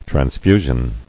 [trans·fu·sion]